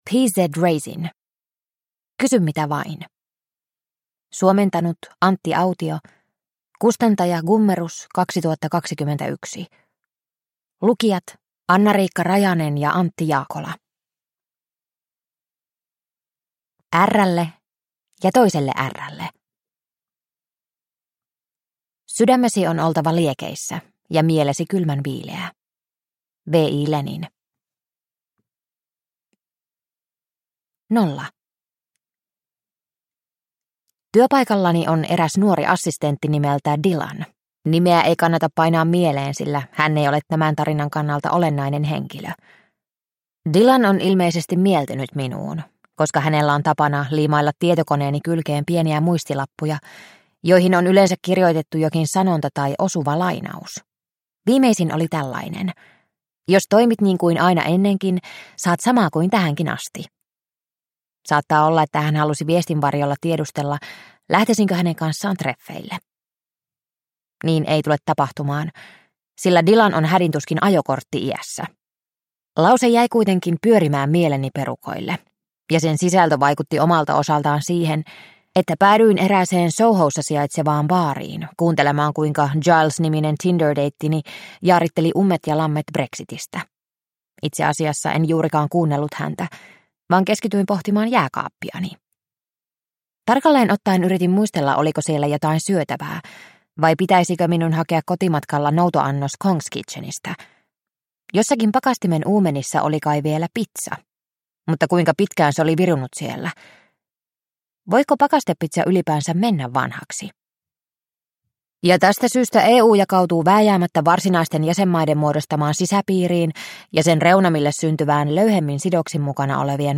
Kysy mitä vain – Ljudbok – Laddas ner